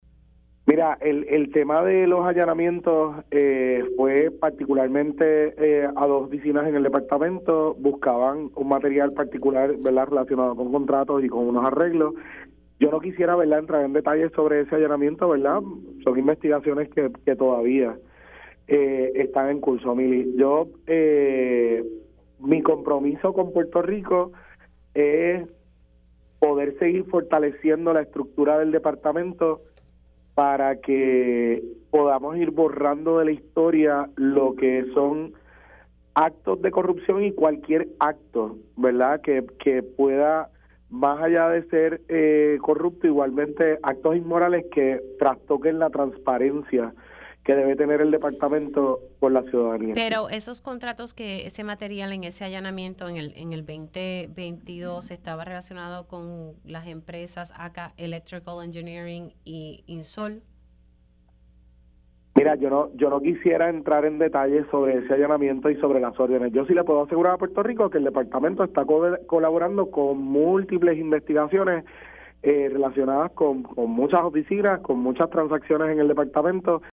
El secretario de Educación, Eliezer Ramos reconoció en Pega’os en la Mañana que el ahora senador por el distrito de Carolina, Héctor Joaquín Sánchez salió del departamento tras denuncias de corrupción en su contra.